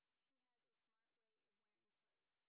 sp26_white_snr10.wav